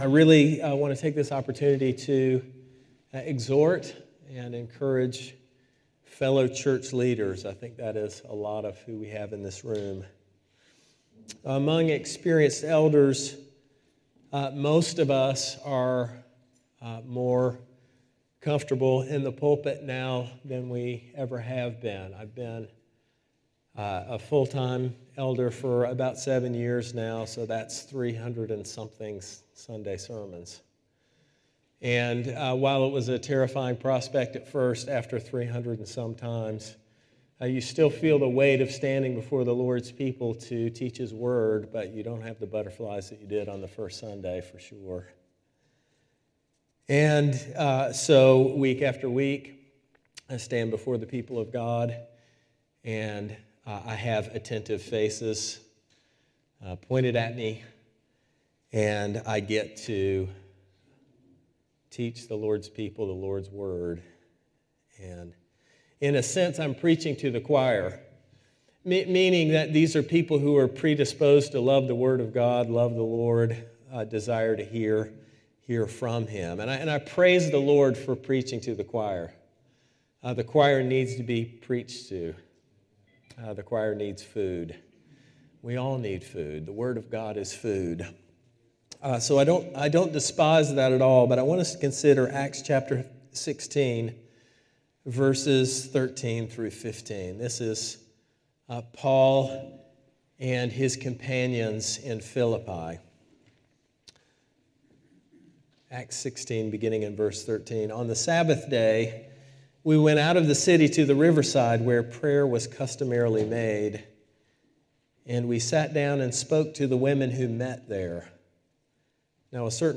0.+Leaders+Supper+--+Panel.mp3